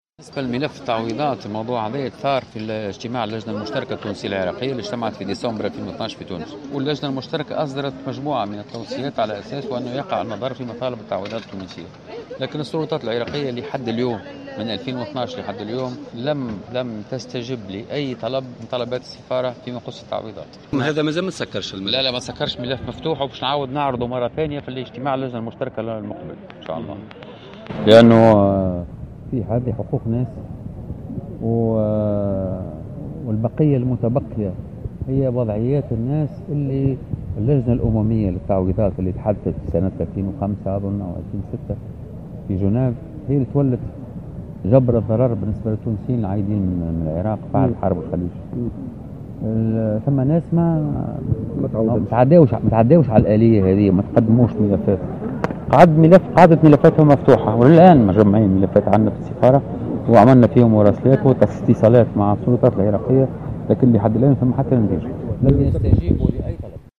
قال السفير التونسي بالعراق سمير في تصريح لمبعوث الجوهرة "اف ام" لبغداد اليوم الخميس 27 أكتوبر 2016 أن مسألة تعويض العائلات التونسية المتضررة من حرب العراق أثير في اجتماع اللجنة المشتركة العراقية التونسية التي انعقدت في تونس سنة 2012 لكن السلطات العراقية لم تستجب الى حد اليوم لأي طلب من طلبات السفارة بخصوص ملف التعويضات.